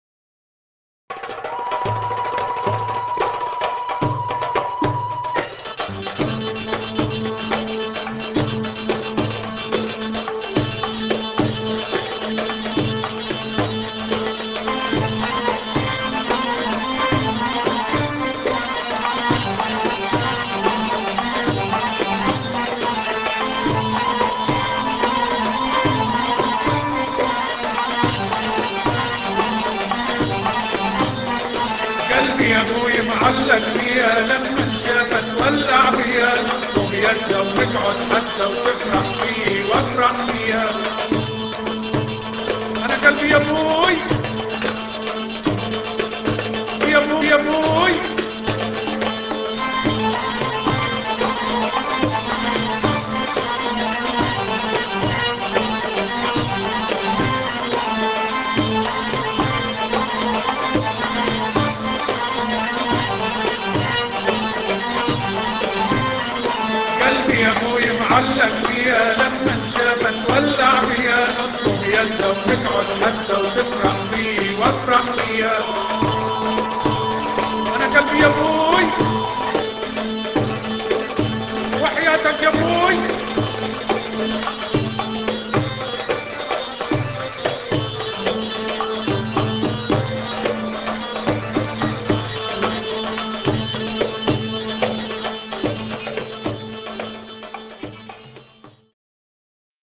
Vocals, Oud, Accordion & Keyboard
Percussion
Tabla
Nay